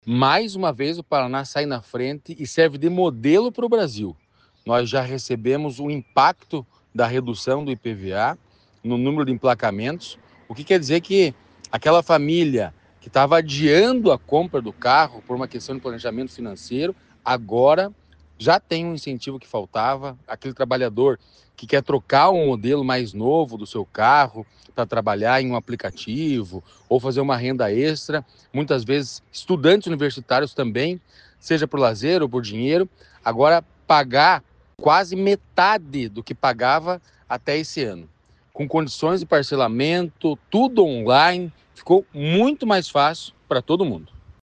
Sonora do diretor-presidente do Detran-PR, Santin Roveda, sobre o aumento no número de emplacamentos de veículos após a redução do IPVA no Paraná